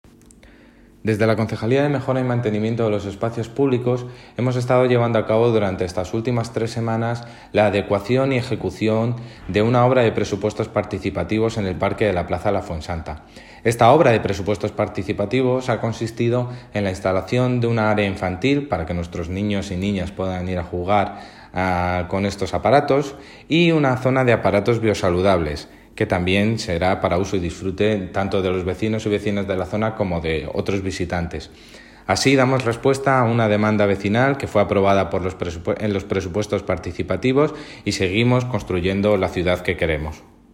Audio - David Muñoz (Concejal de Mejora y Mantenimiento de los Espacios Publicos) Sobre parque La Fuensanta